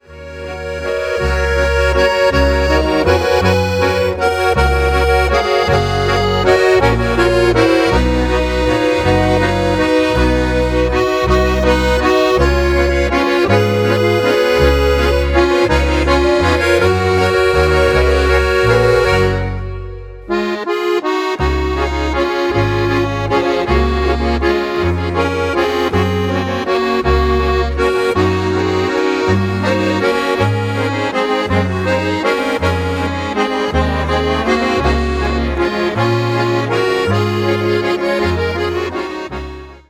Walzer